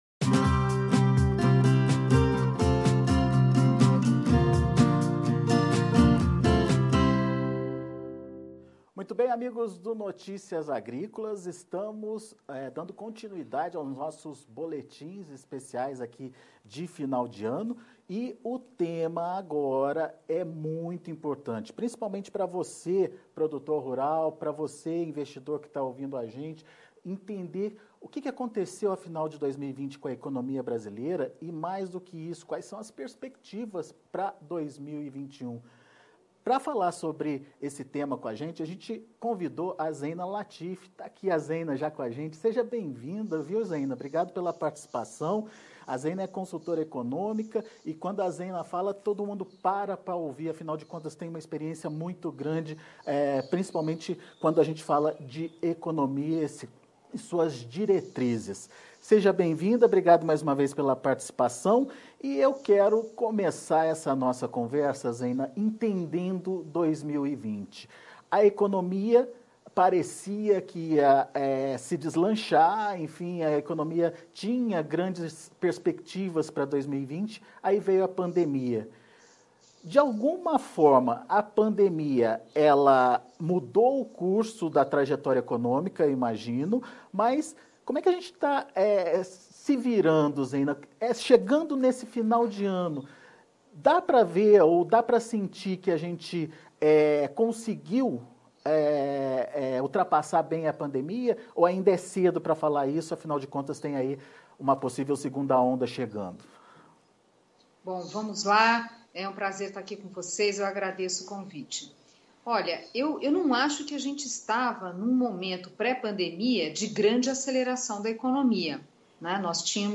Podcast Zeina Latif analisa a consistência da recuperação econômica no Brasil e se diz otimista com Agro para 2021 Download Em entrevista ao Notícias Agrícolas, Zeina Latif destaca alguns pontos importantes a serem observados no próximo ano.